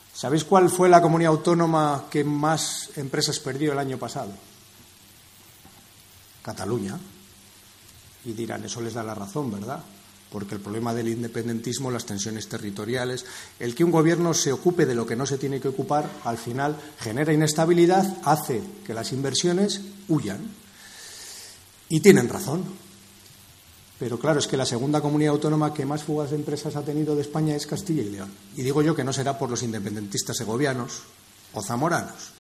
En la inauguración de las jornadas ha participado el líder regional del PSOE.
Luis Tudanca, secretario general del PSOE en Castilla y León